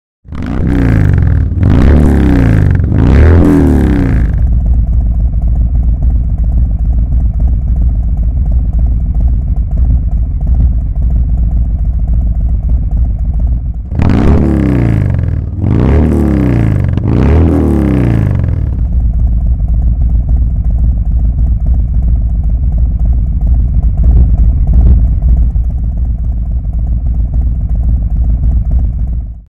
Audio con Db Killer